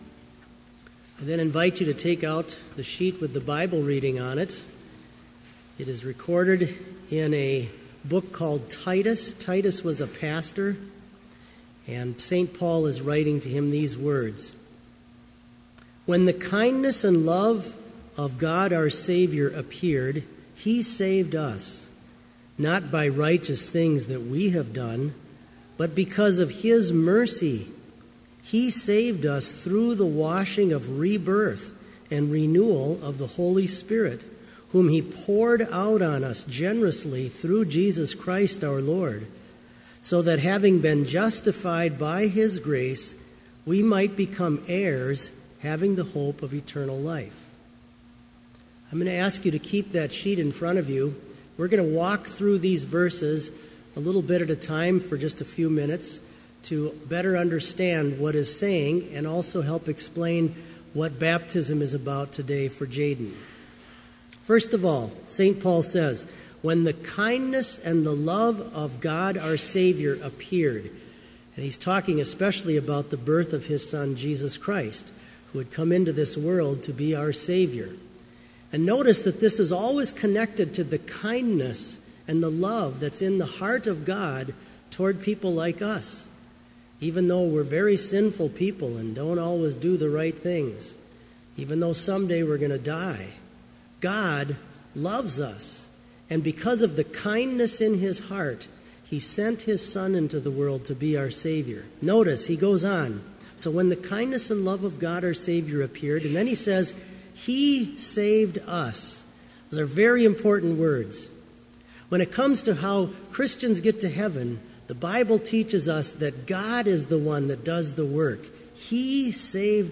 Sermon Only
This Chapel Service was held in Trinity Chapel at Bethany Lutheran College on Wednesday, August 5, 2015, at 10 a.m. Page and hymn numbers are from the Evangelical Lutheran Hymnary.